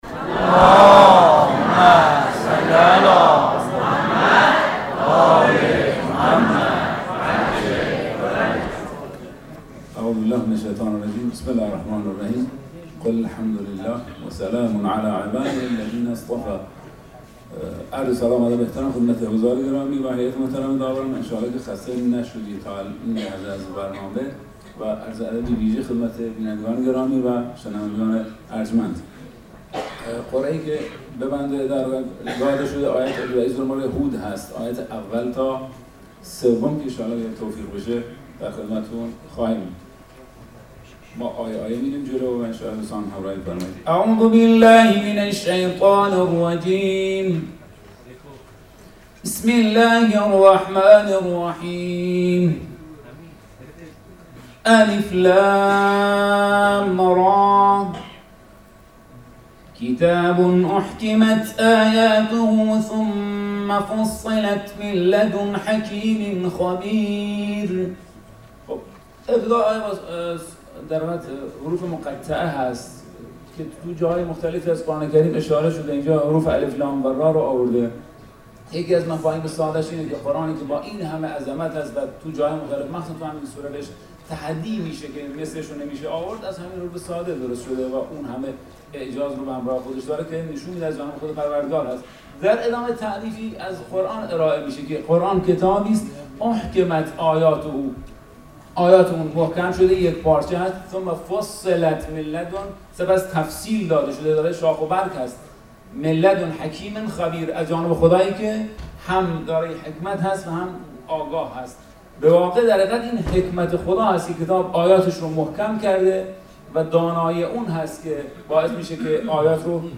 برگزیده مخاطب پسندترین بخش رقابتهای قرآنی کشور مشخص شد صوت تفسیرگویی - تسنیم